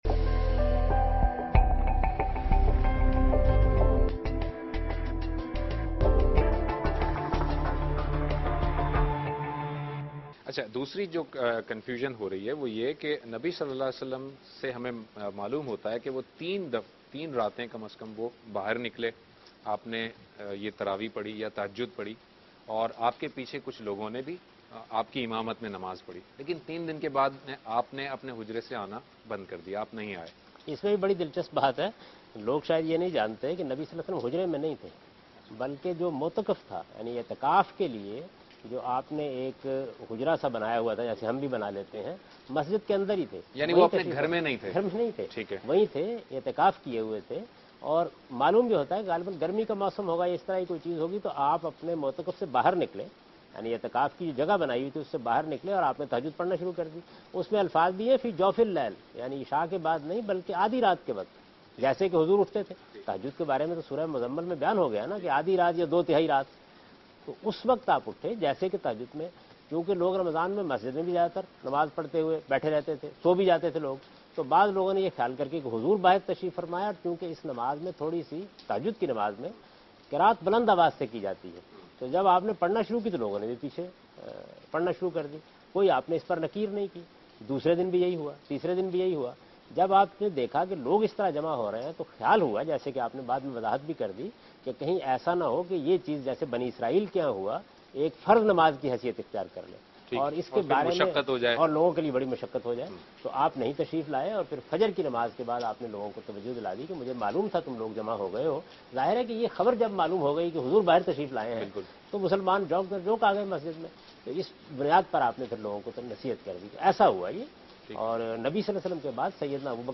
Dunya Tv program Deen-o-Danish Special. Topic: Traveeh Ki Haqeeqat.
دنیا ٹی وی کے پروگرام دین ودانش میں جاوید احمد غامدی تراویح کی حقیقت کے متعلق گفتگو کر رہے ہیں